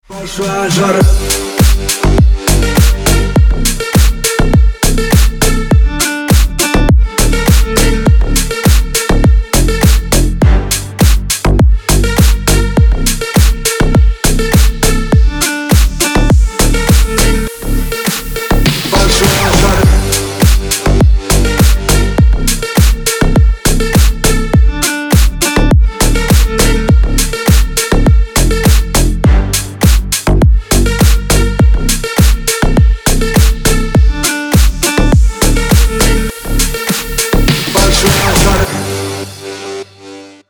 • Качество: Хорошее
• Песня: Рингтон, нарезка